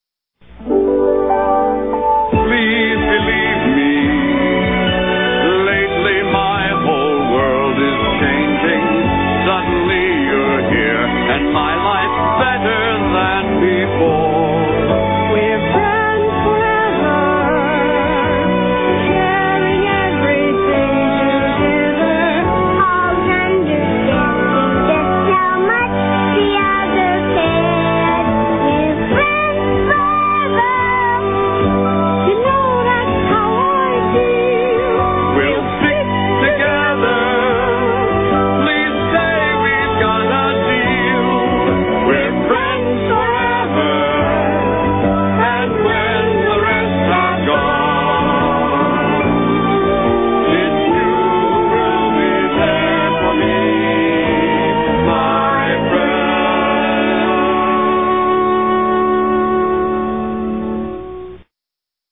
Tags: TV Songs actors theme song show